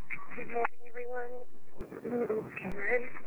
EVP's From Some Very Friendly Spirits